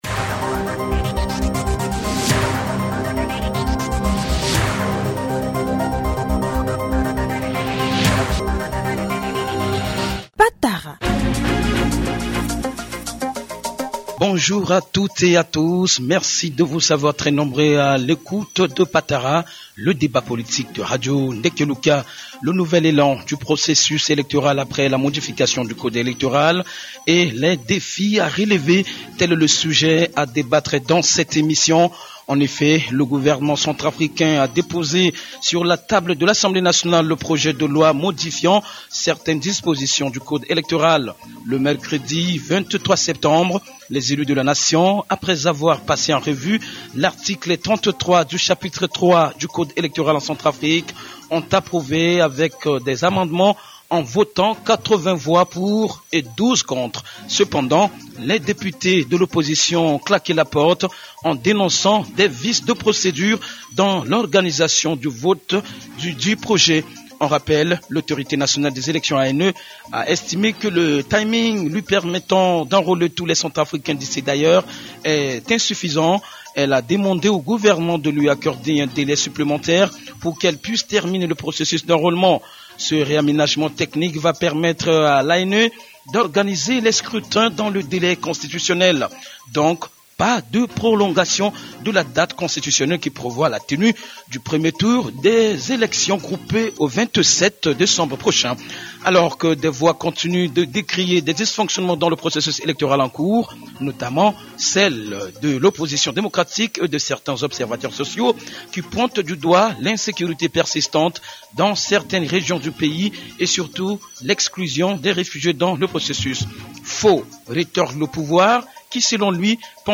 Pour en débattre, l’équipe Patara reçoit Jean Symphorien Mapenzi, 1er vice-président de l’Assemblée nationale, cadre du Mouvement cœurs unis; Augustin Agou, député du 1er arrondissement de Bangui, président du parti politique RDD; Joseph Bendounga, opposant et président du parti MDREC et Fidèle Gouandjika, ministre-conseiller spécial du président de la République.